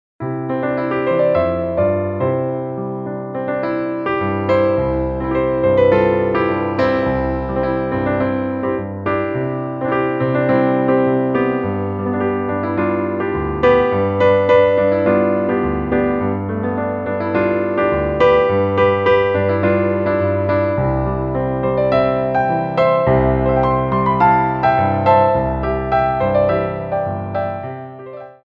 4/4 (16x8)